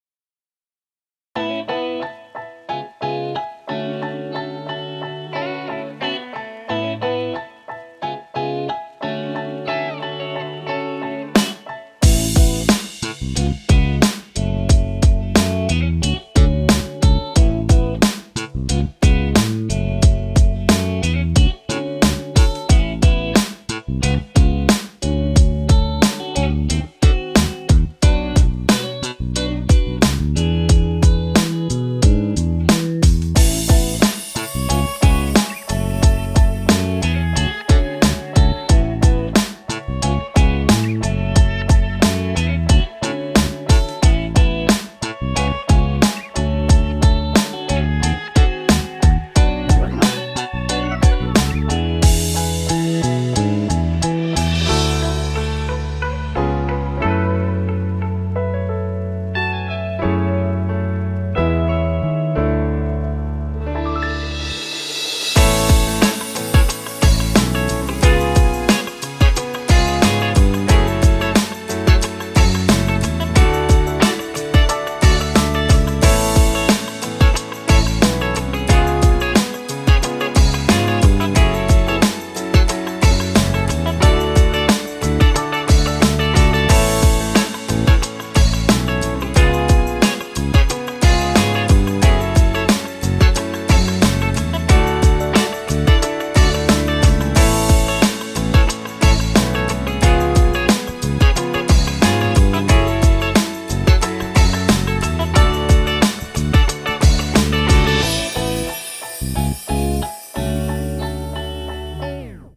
Instrumental Ver.